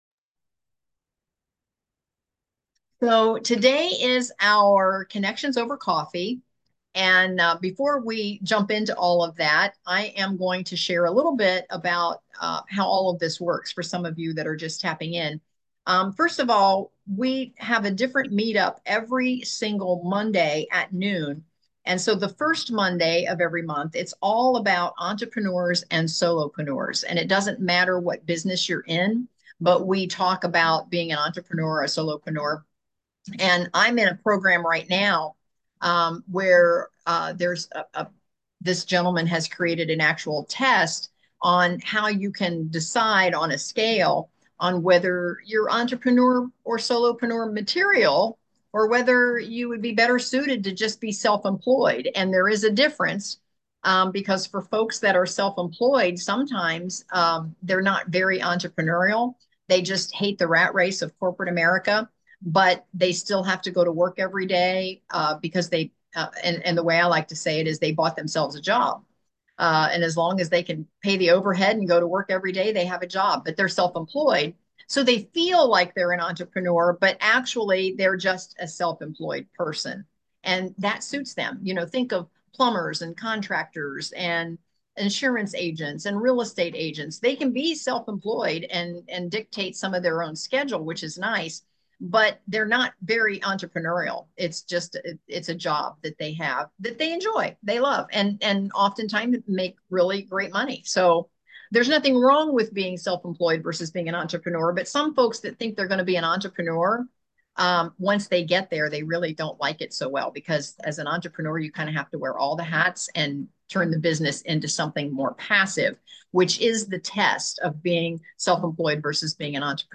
Fit As a Fiddle Plus | Interview